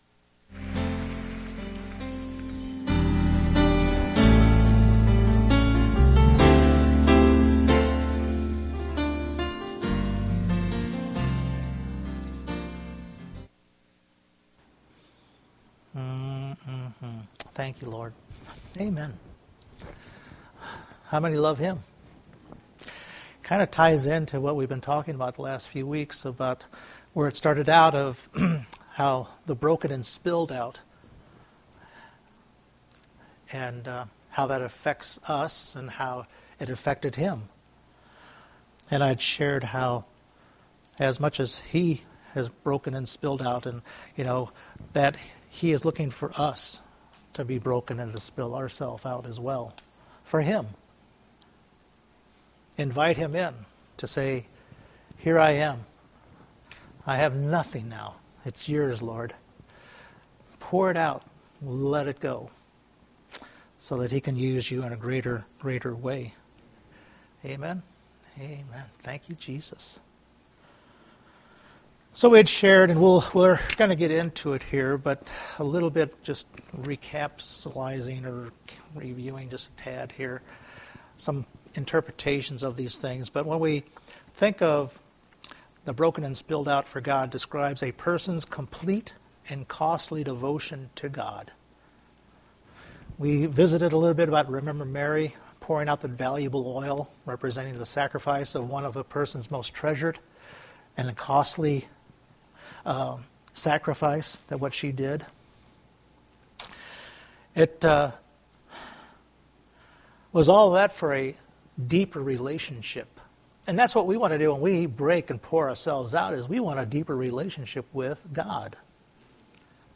Several Service Type: Sunday Morning We continue to follow this thread for our lives to be offered up for God to use and have.